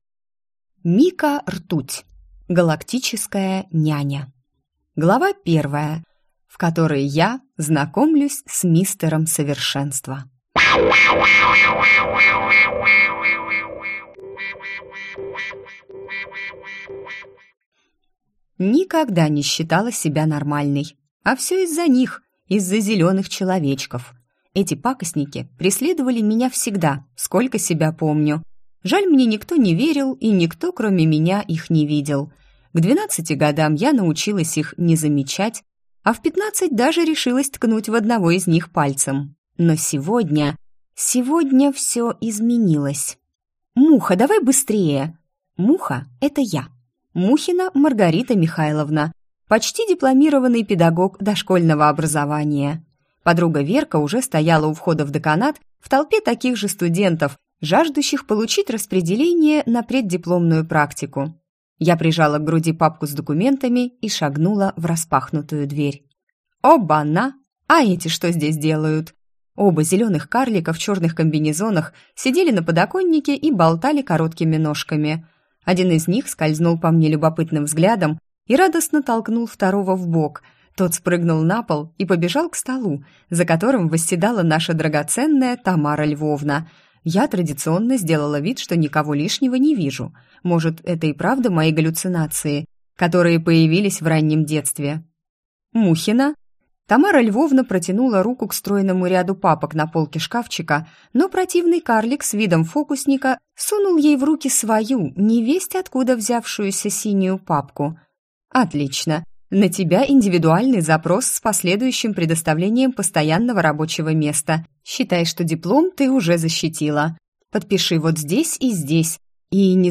Аудиокнига Галактическая няня | Библиотека аудиокниг